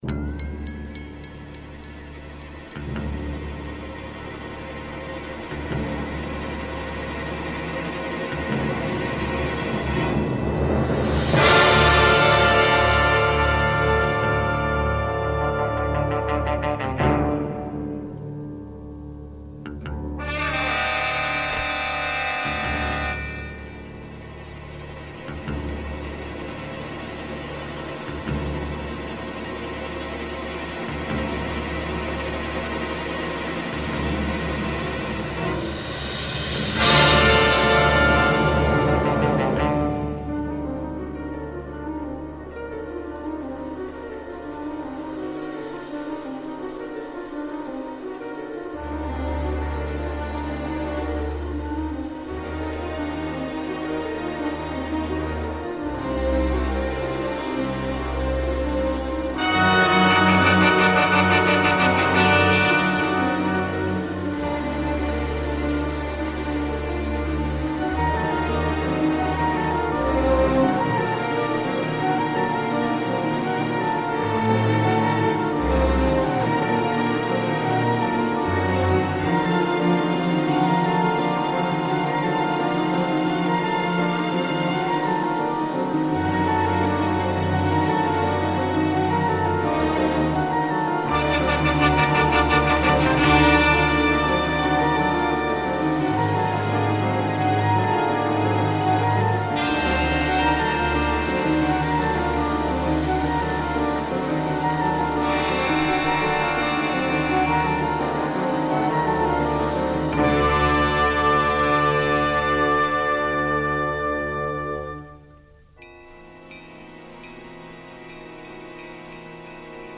percussif et cuivré à doses savantes.